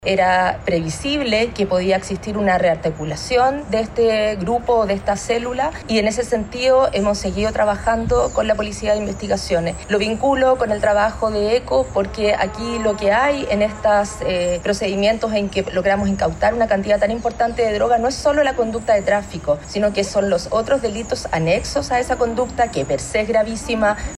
Por su parte, la fiscal regional de Valparaíso, Claudia Perivancich, dijo que “era predecible que podía existir una rearticulación de este grupo y esta célula, y en ese sentido hemos seguido trabajando con la Policía de Investigaciones”.
fiscal-tren-del-mar.mp3